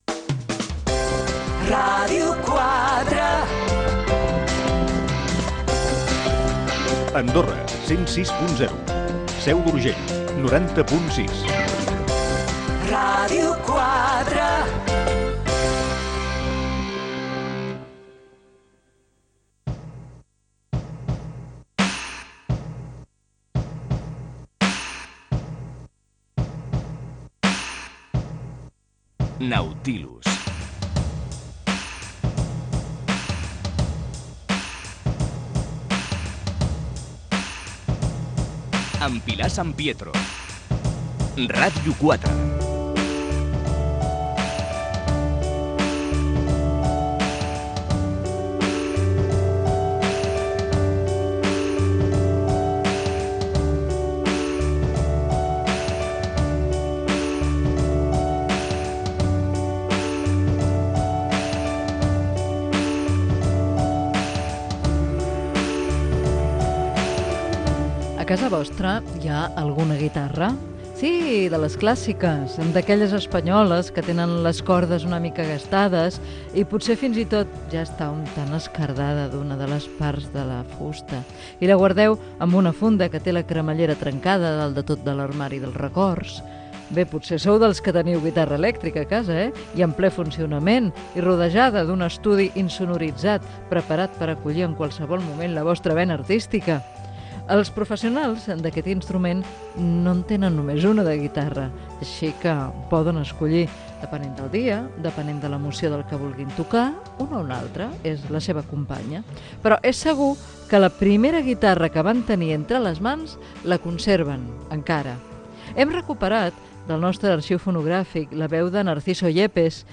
Indicatiu de la ràdio amb algunes de les freqüències, careta del programa, comentari sobre la guitarra
Gènere radiofònic Cultura